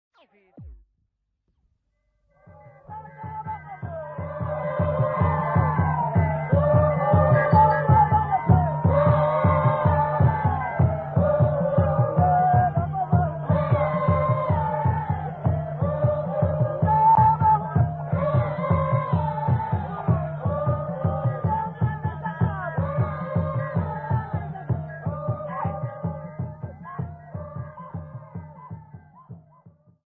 Venda men, women and children
Folk Music
Field recordings
Africa South Africa Limpopo Province f-sa
sound recording-musical
Indigenous music